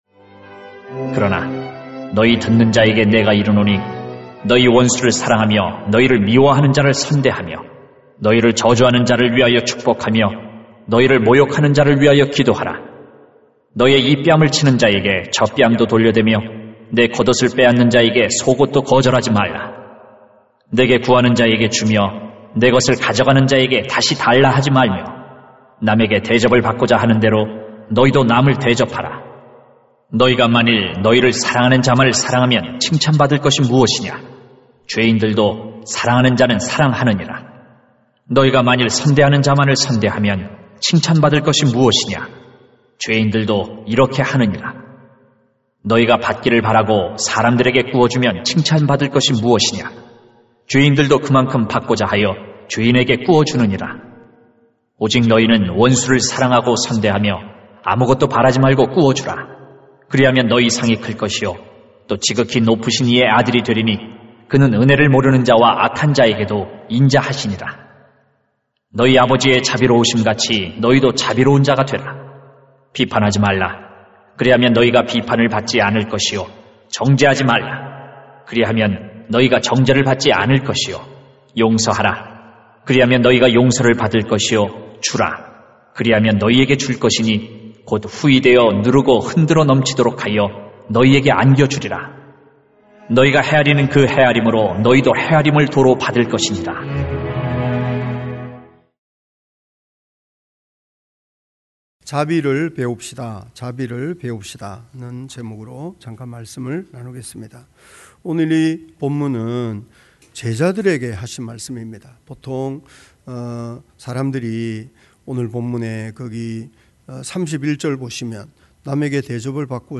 [눅 6:27-38] 자비를 배웁시다 > 새벽기도회 | 전주제자교회